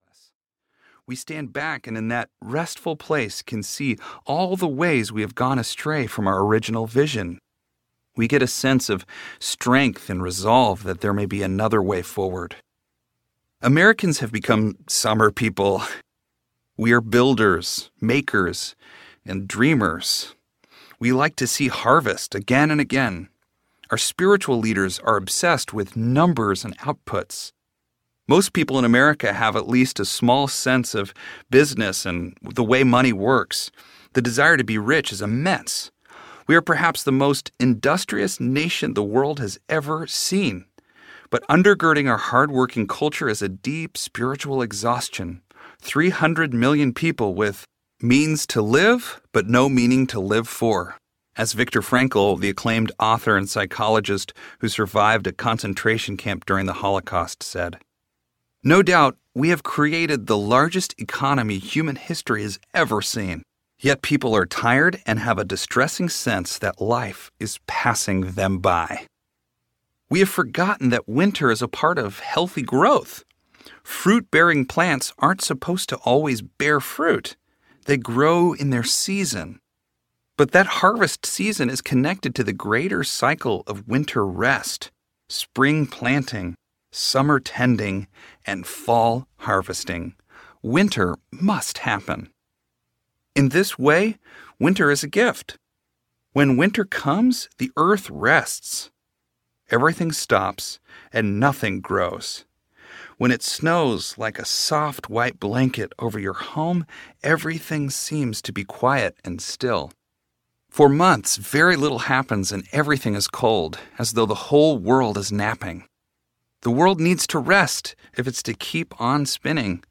You Are Beloved Audiobook